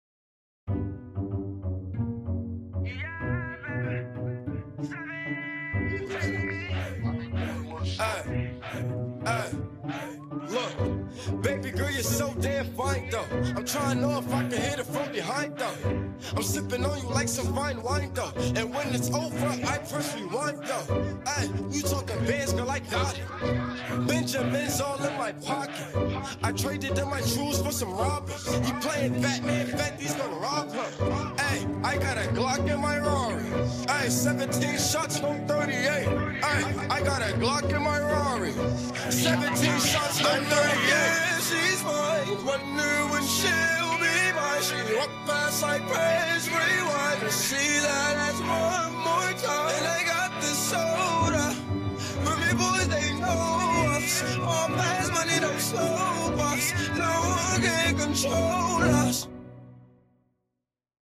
I decided to only do the song through the first chorus.